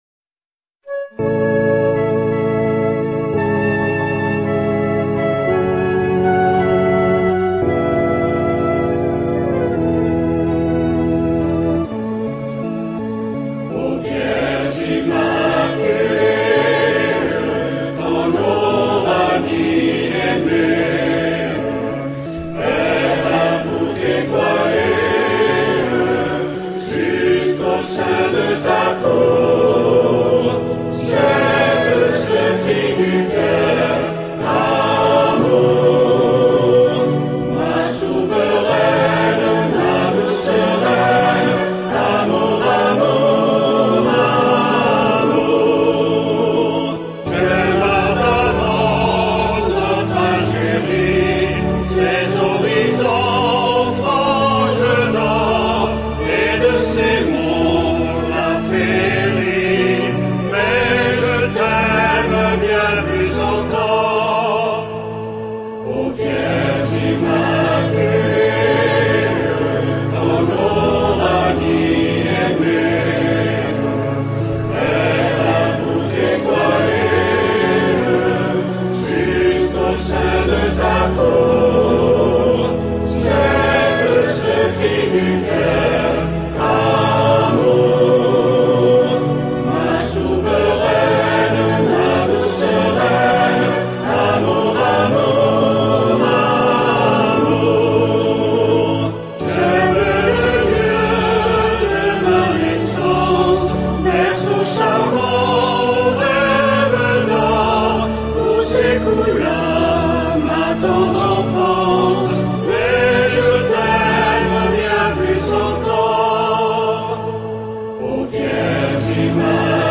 Ci-dessous le chant marial.)